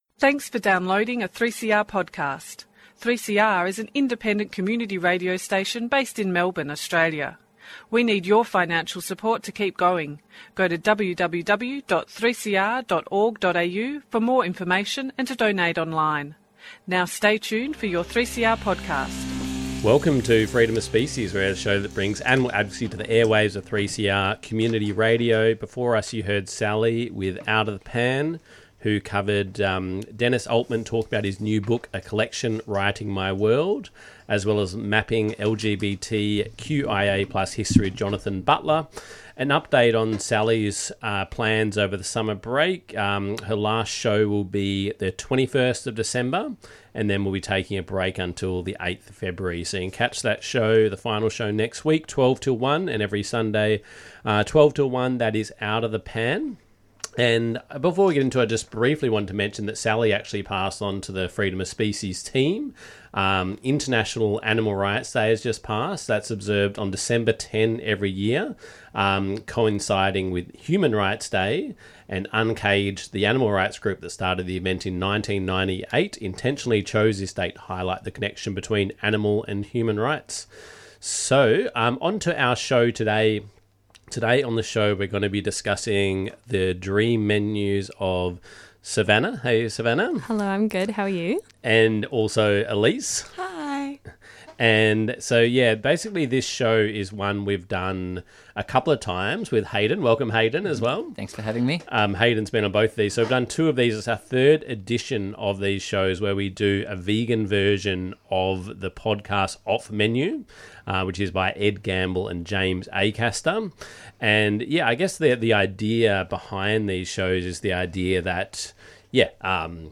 Animal advocacy on the airwaves, hosted by a team of local animal advocates.